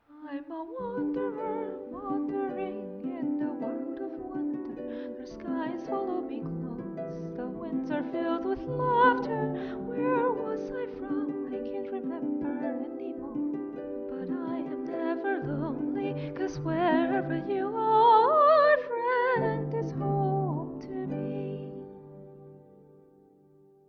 The folk song that Veloce hears/sings along to in the scene in book 5, page 97 :D